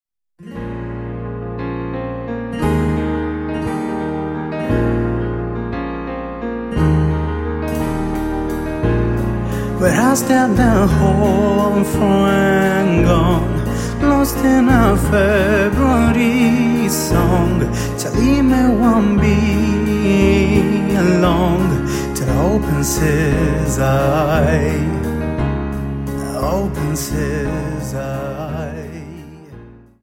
Dance: Viennese Waltz 58